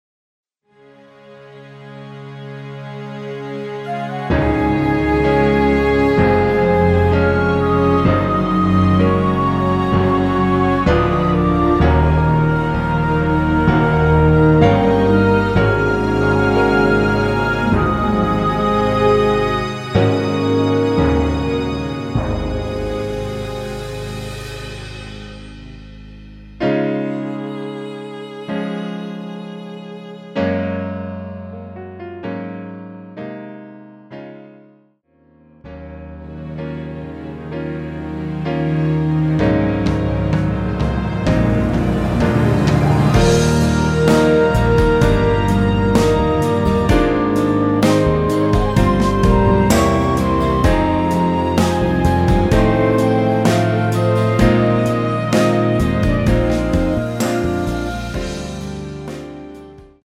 미리듣기는 처음부터 35초 까지와 중간 ~사랑해줘서 그댄 아름다운 신부 ~부분 입니다.
Db
<전주 26초 정도> 원곡은 약 50초
앞부분30초, 뒷부분30초씩 편집해서 올려 드리고 있습니다.
중간에 음이 끈어지고 다시 나오는 이유는